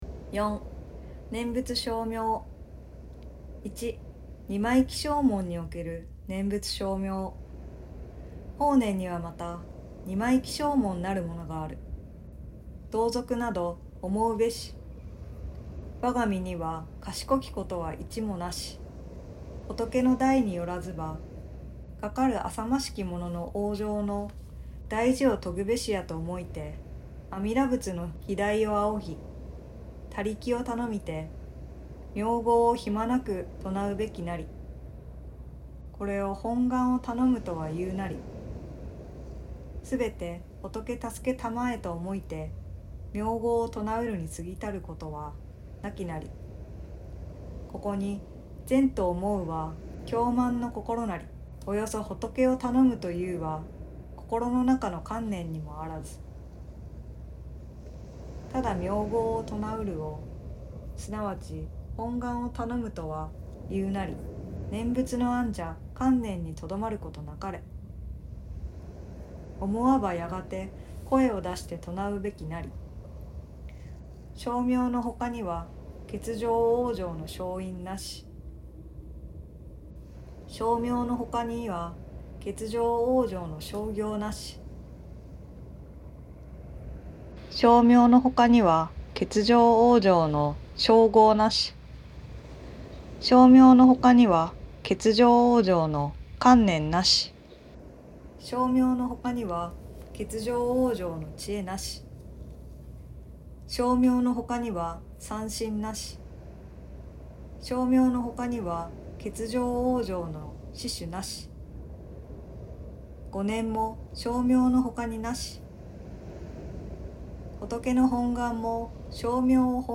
心を豊かにする朗読。